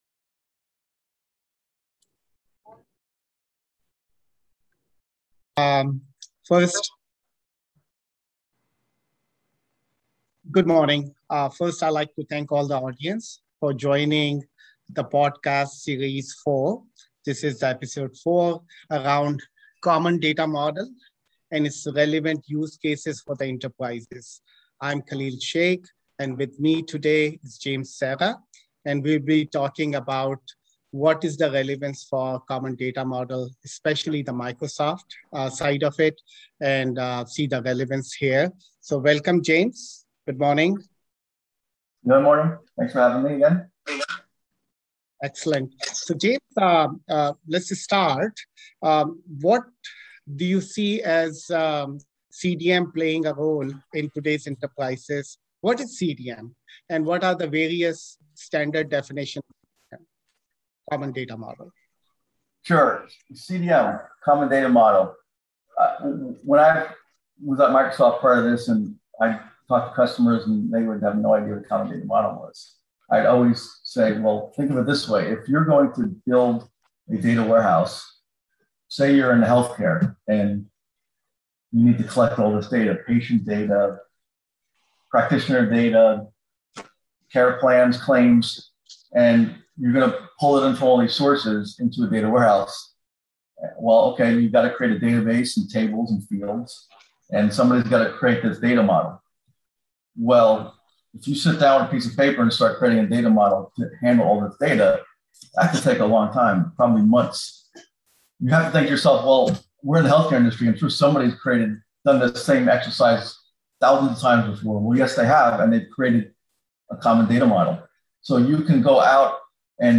A podcast series where data and analytics leaders discuss enterprise AI, data modernization, and digital transformation strategies.